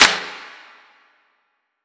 TC2 Clap12.wav